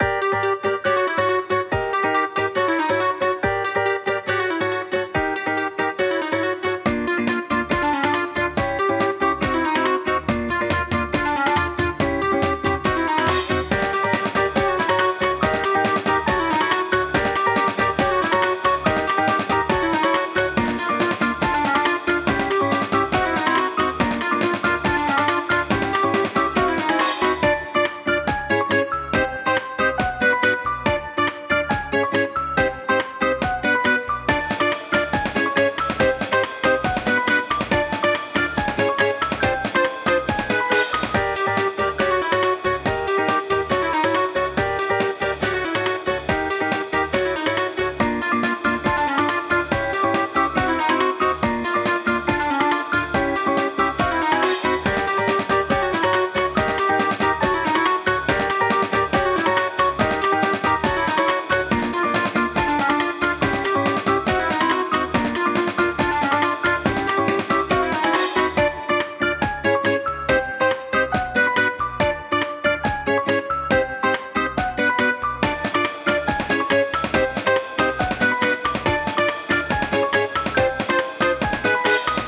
カジノやポーカーなどのシーンにピッタリな一曲です！
ピアノのアルペジオとアコギが特にお気に入りポイント！
ループ：◎
BPM：140
キー：Gm
ジャンル：あかるい、おしゃれ
楽器：ストリングス、ピアノ、シンセサイザー、ギター